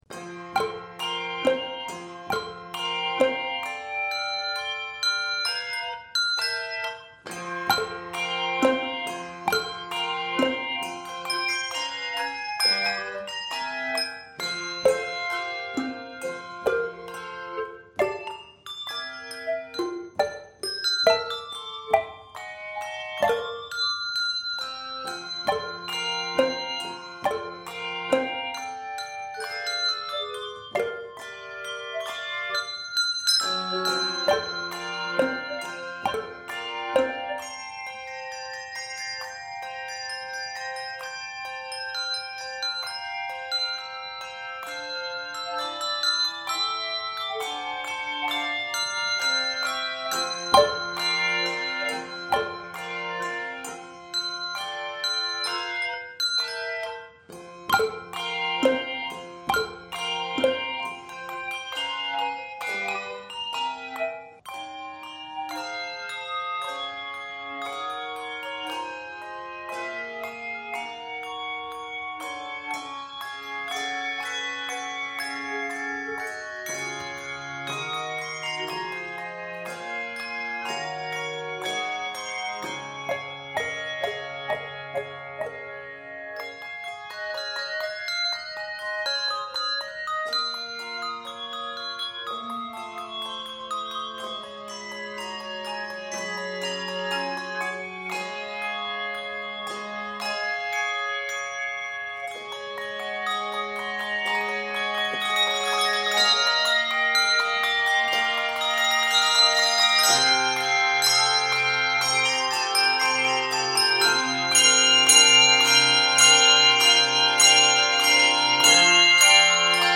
Keys of F Major and Db Major.